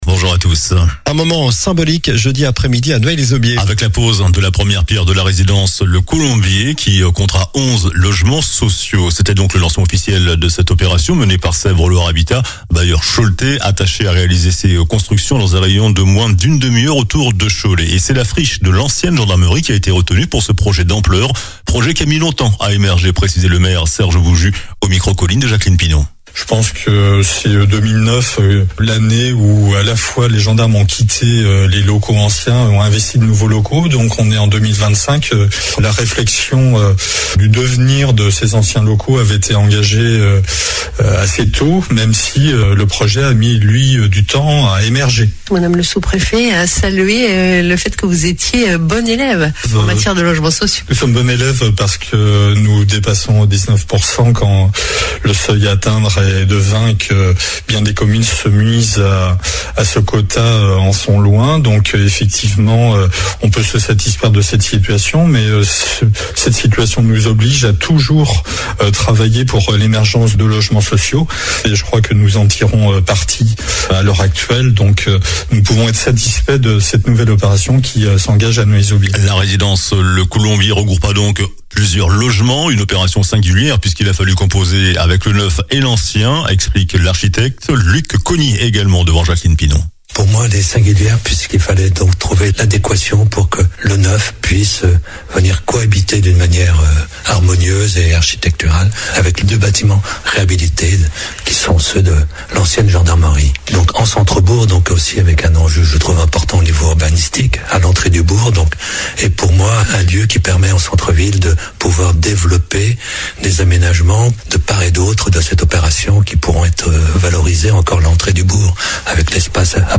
Journal du samedi 11 octobre